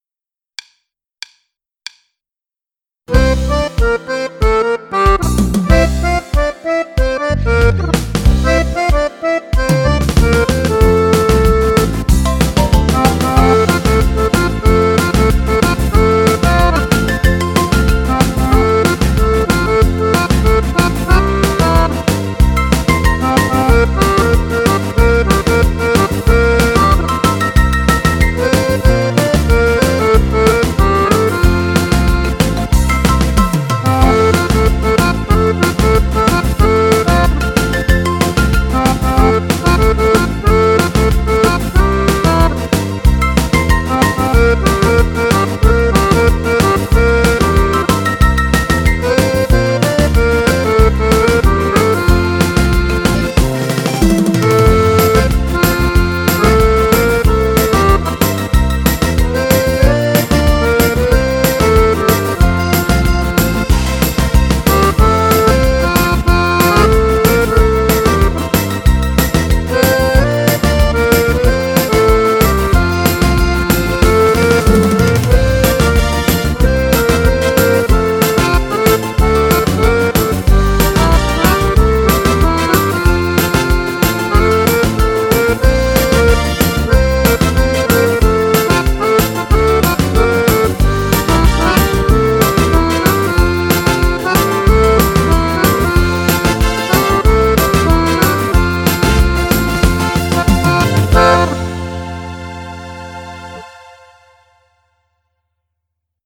Ma kipróbáltam a PA4X-en a "MIDI to STYLE" konvertert!